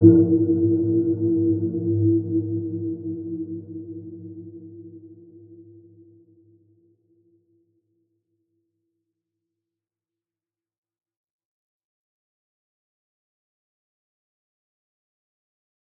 Dark-Soft-Impact-E4-mf.wav